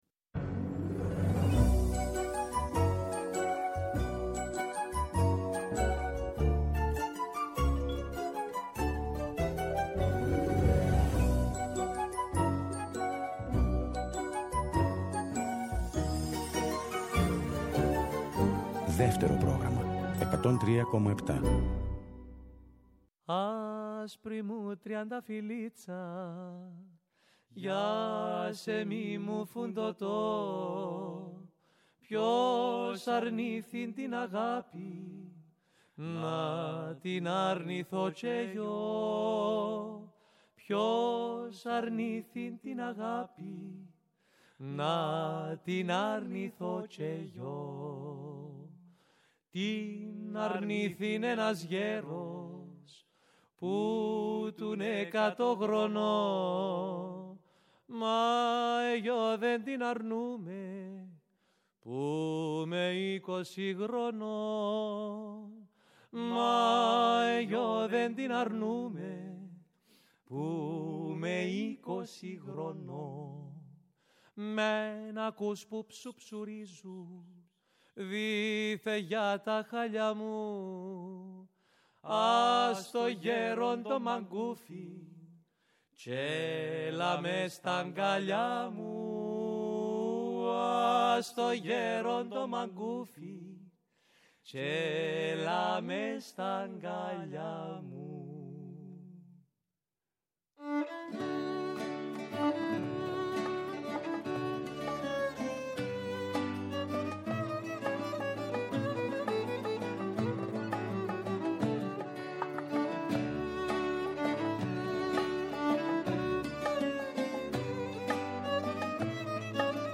Η παραδοσιακή μας μουσική, το δημοτικό τραγούδι, οι αγαπημένοι μας δημιουργοί από το παρελθόν, αλλά και η νεότερη δραστήρια γενιά των μουσικών, συνθέτουν ένα ωριαίο μουσικό παραδοσιακό γαϊτανάκι.